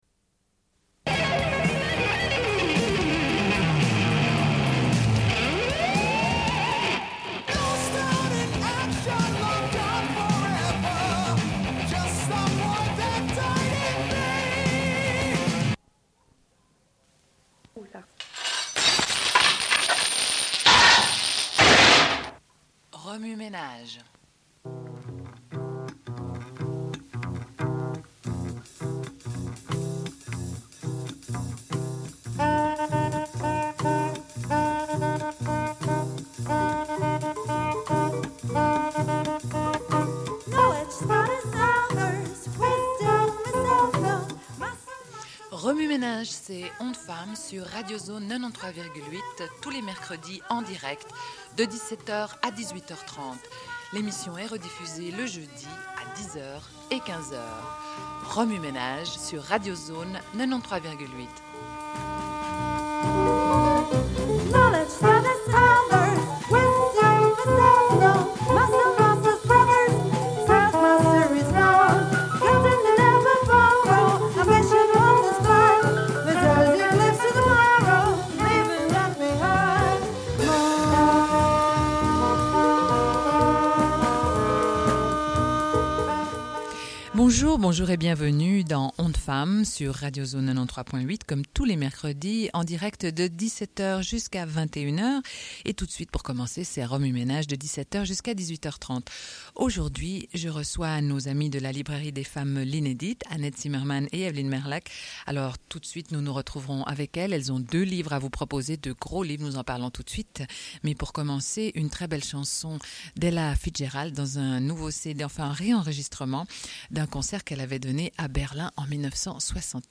Une cassette audio, face A31:31